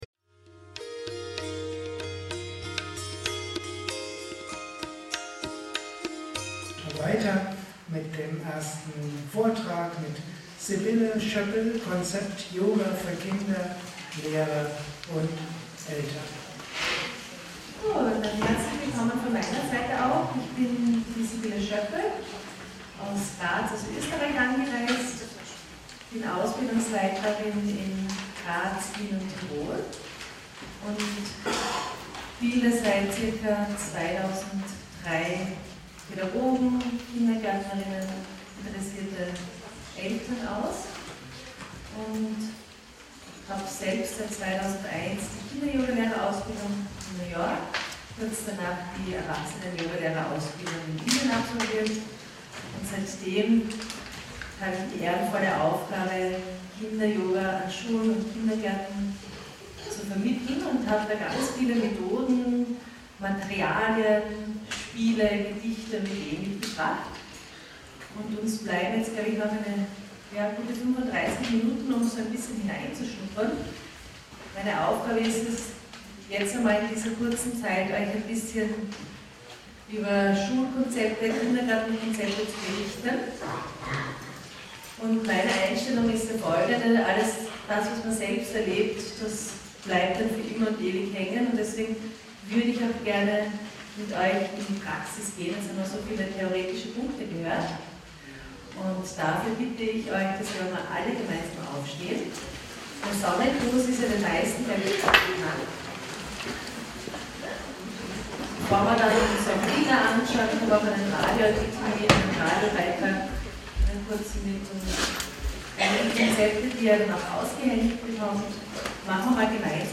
Vortrag und Workshop